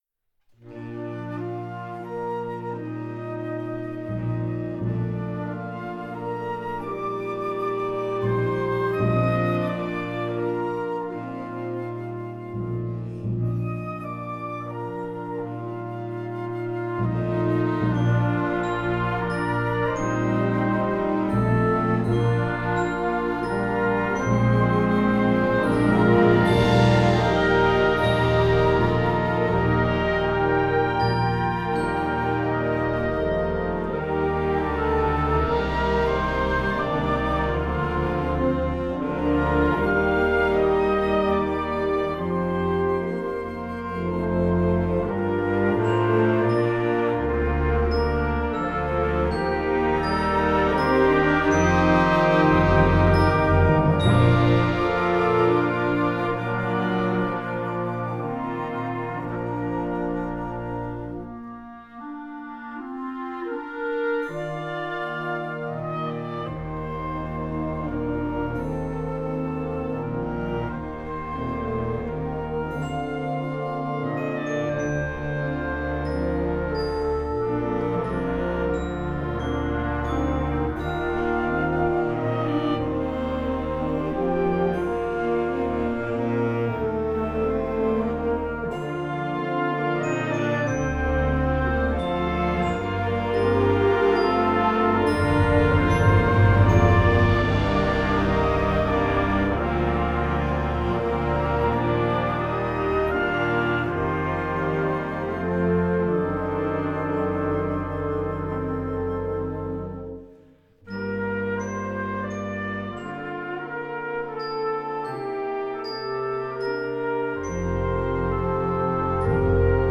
Gattung: Konzwertwerk für Jugendblasorchester
Besetzung: Blasorchester